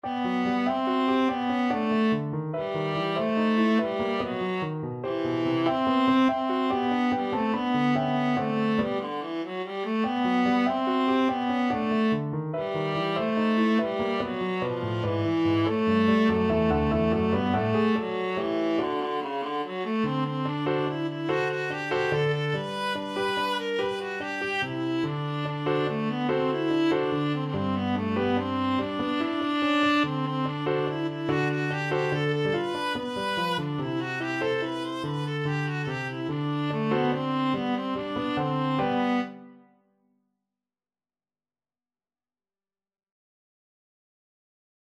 Free Sheet music for Viola
Viola
6/8 (View more 6/8 Music)
G major (Sounding Pitch) (View more G major Music for Viola )
. = 96 Allegro (View more music marked Allegro)
Classical (View more Classical Viola Music)